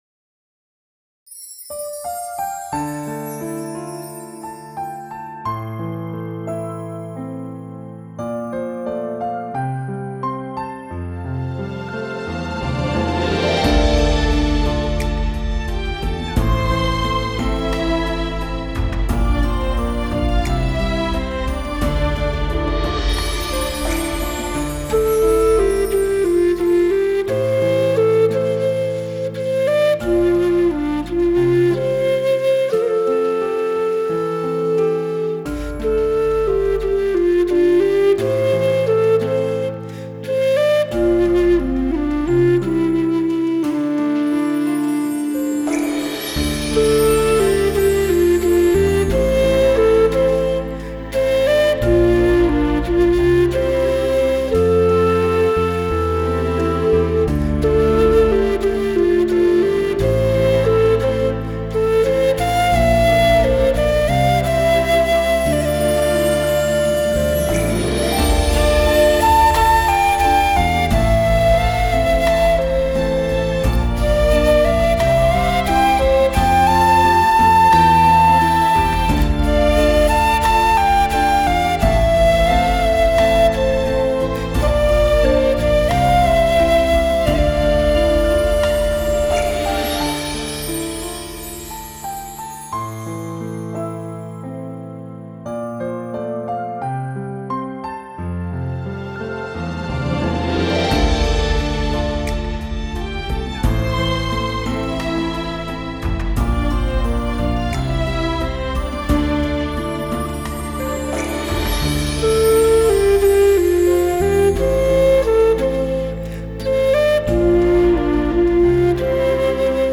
蕭演奏：天意 | 法輪大法正見網